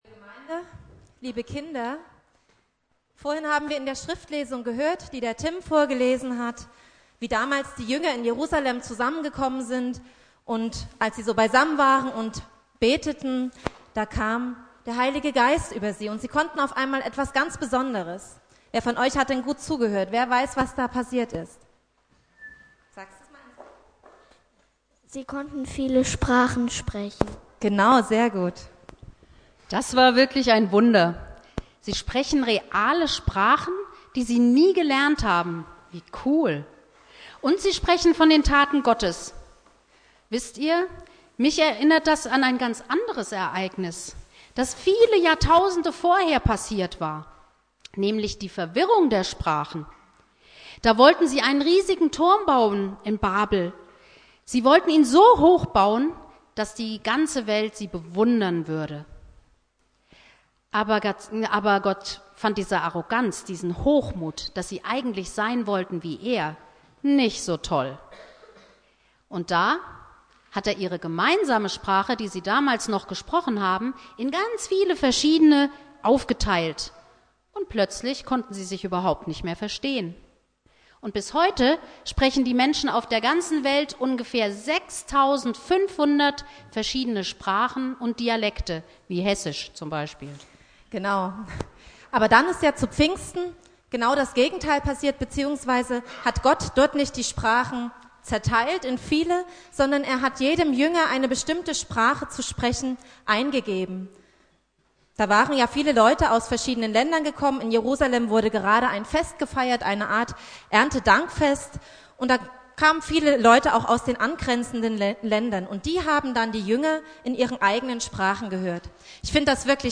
Dialogpredigt zu Pfingsten (Familiengottesdienst) Bibeltext: Apostelgeschichte 2,1-13